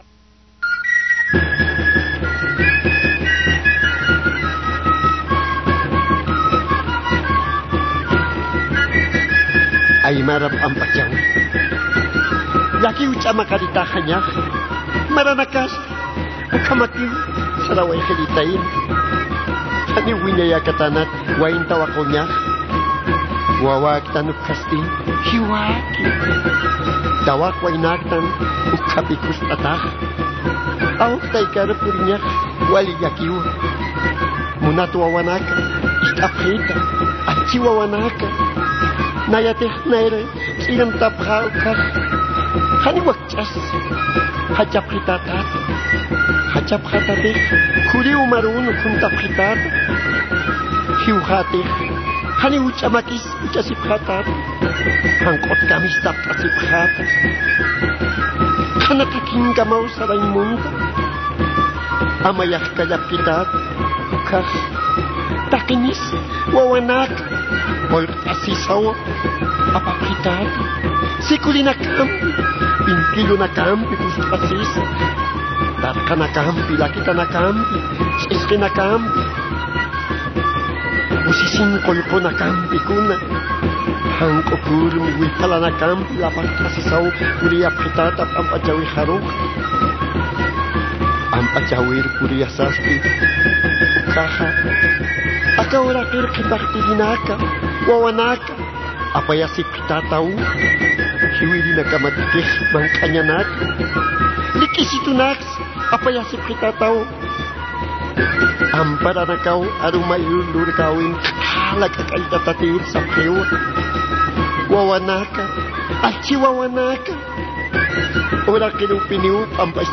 Pinkill warurt'awi